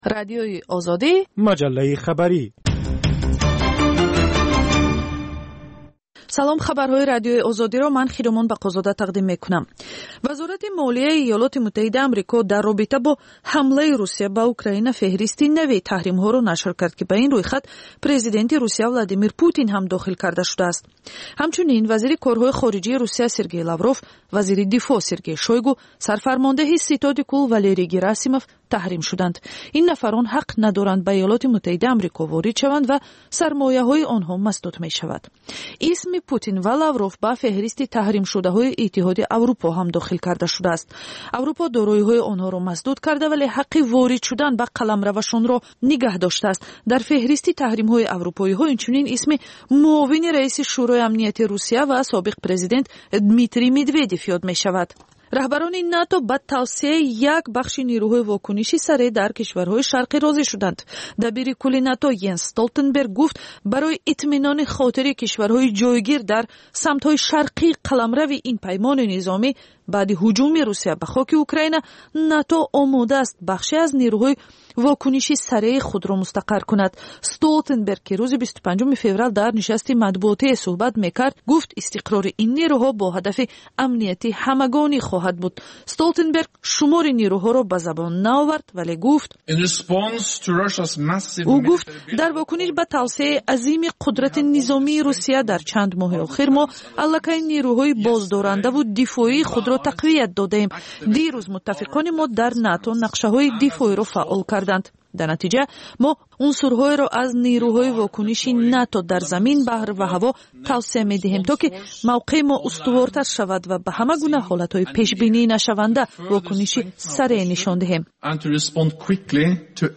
Тозатарин ахбори ҷаҳон, минтақа ва Тоҷикистон, таҳлилу баррасиҳо, мусоҳиба ва гузоришҳо аз масъалаҳои сиёсӣ, иҷтимоӣ, иқтисодӣ, фарҳангӣ ва зистмуҳитии Тоҷикистон.